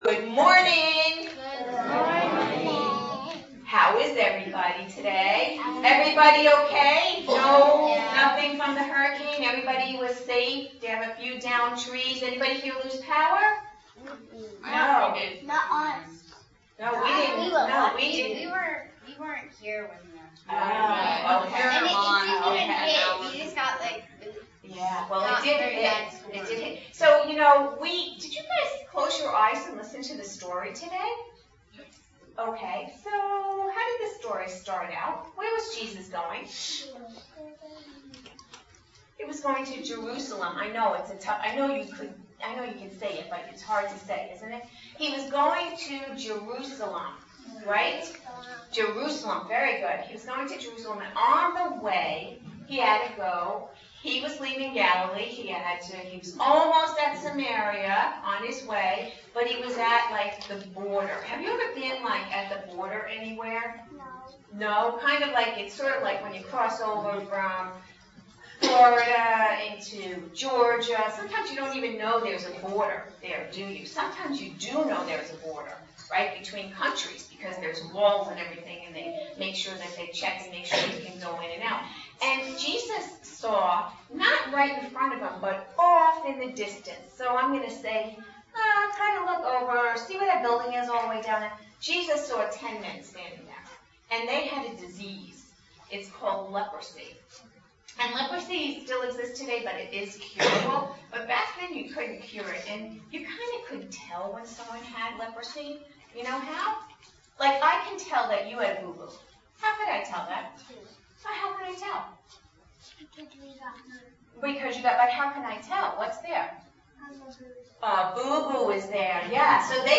Children's Sermons